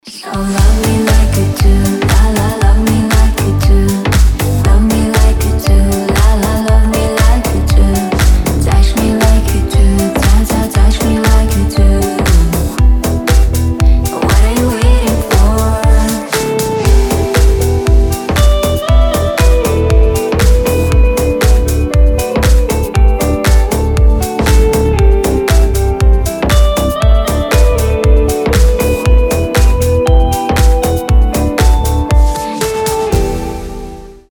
• Качество: 320, Stereo
deep house
мелодичные
красивый женский голос